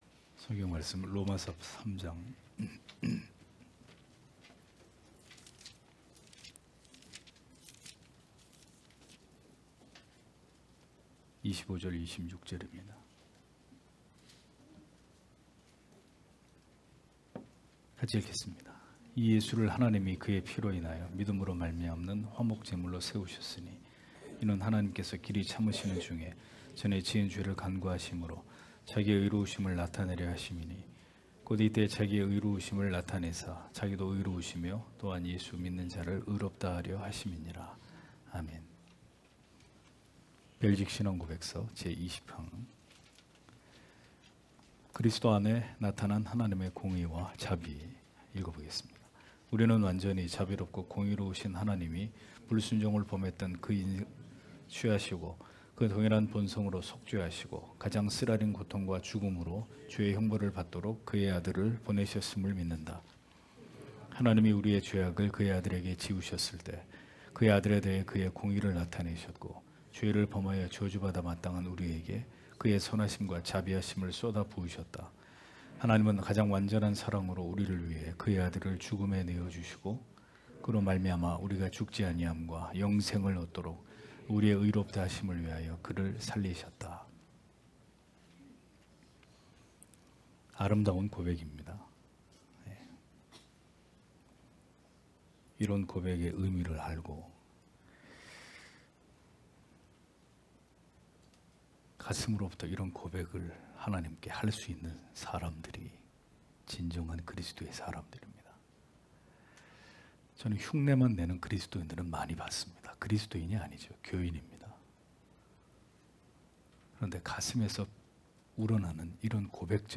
주일오후예배 - [벨직 신앙고백서 해설 21] 제20항 그리스도 안에 나타난 하나님의 공의와 자비 (롬 3장 25-26)
* 설교 파일을 다운 받으시려면 아래 설교 제목을 클릭해서 다운 받으시면 됩니다.